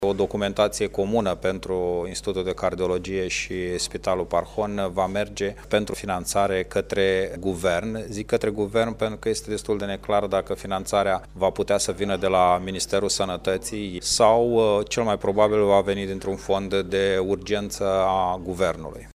Viceprimarul Iaşului, Radu Botez, a declarat că situaţia de la Institutul de Cardiologie din Iaşi, după zece zile de la incendiu, se politizează